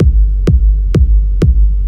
• Dark Kick with Reverb Big.wav
Dark_Kick_with_Reverb__Big-2_LIf.wav